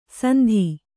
♪ sandhi